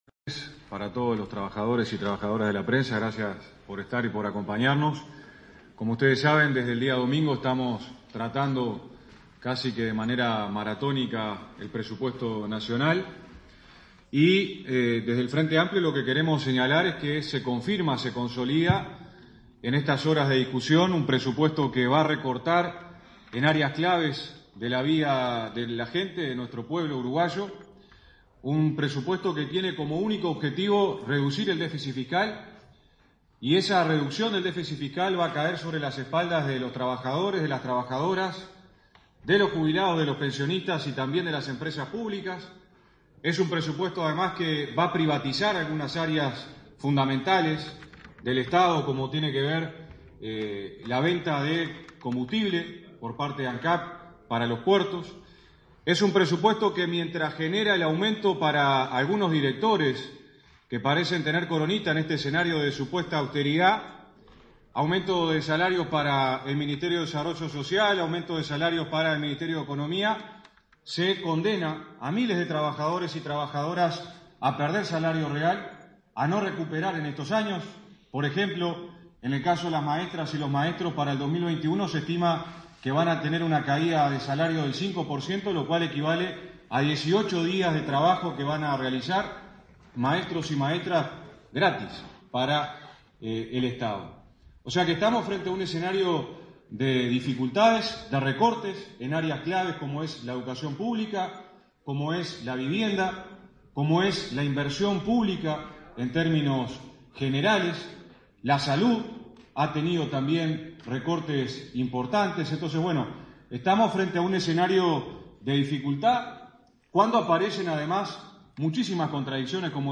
En conferencia de prensa el diputado Gerardo Núñez y la diputada Bettiana Díaz enfatizaron que se consolida un Presupuesto Nacional que va a recortar en áreas claves para reducir el déficit fiscal a costa de trabajadores, jubilados, pensionistas, al tiempo que privatiza algunas áreas fundamentales del Estado, como por ejemplo, la venta de combustibles de ANCAP en puertos.